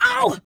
42 RSS-VOX.wav